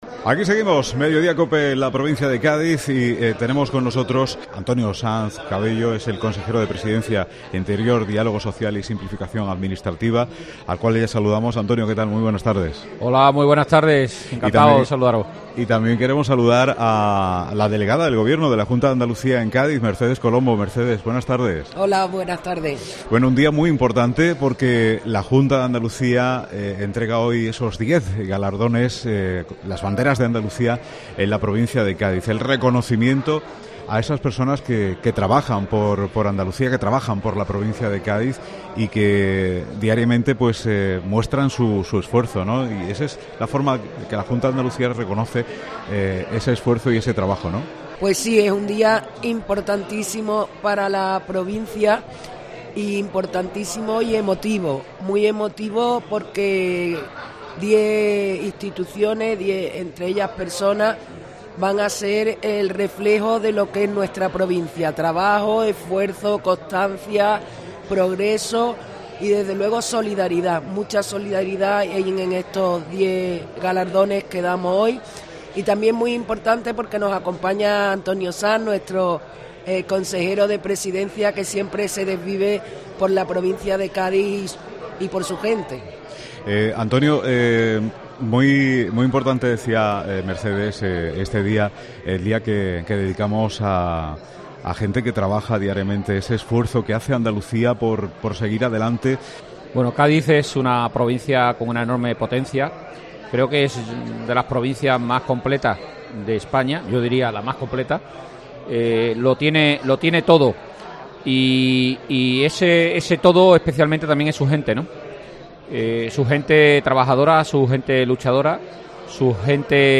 Antonio Sanz Cabello, Consejero de la Presidencia, Interior, Diálogo Social y Simplificación Administrativa han estado en los micrófonos de COPE hablando de estos premios y la importancia de reconocer la labor de colectivos y personas que trabajan por Andalucía.